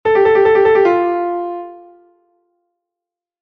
trino_simple.mp3